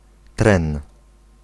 Ääntäminen
Ääntäminen Tuntematon aksentti: IPA: [trɛn] Haettu sana löytyi näillä lähdekielillä: puola Käännös Konteksti Ääninäyte Substantiivit 1. train armeija UK US 2. threnody US Suku: m .